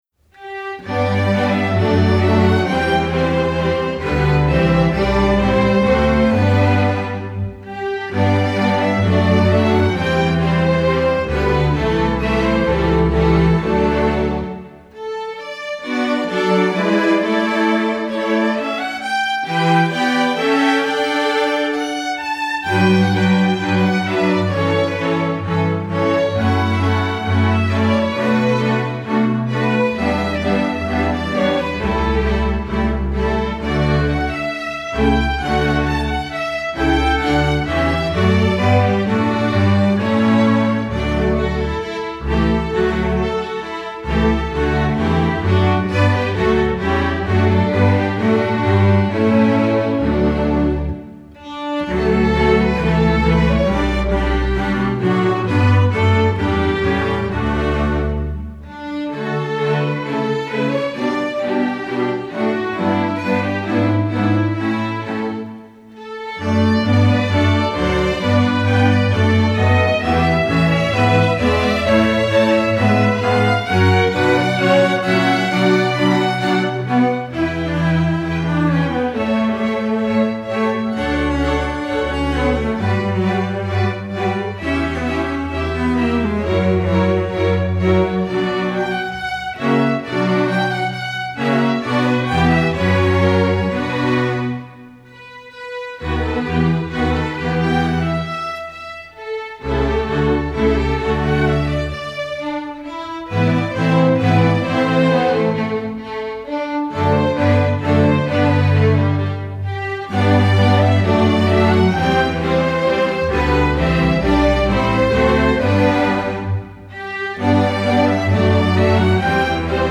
Instrumentation: string orchestra
masterwork arrangement